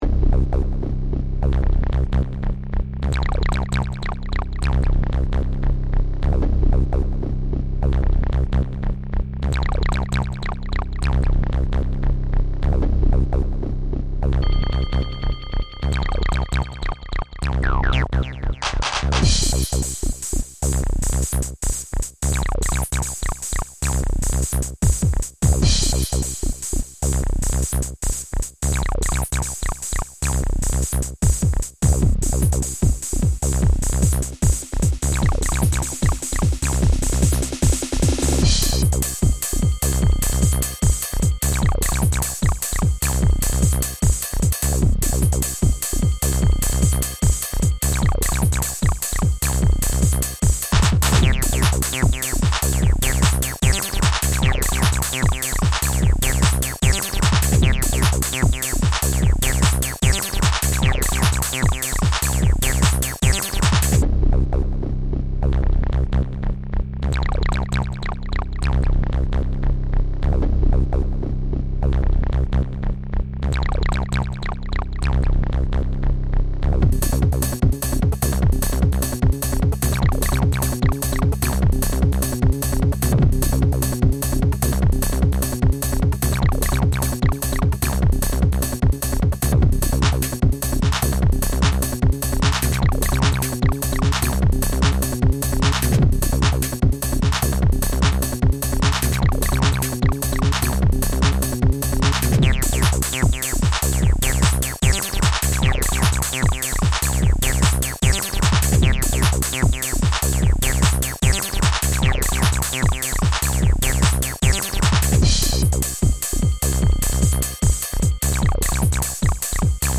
From post-production houses to disco floors, from high-powered live sound to rock, dub, and metal studio productions, the characteristic rumble of the subharmonic synthesizer has been an engineer's best-kept secret for years.
The variable lowpass filter is provided to shave off the higher harmonics of the overdrive and create a smooth low end - or you can leave some of them in, for raspier bass sounds.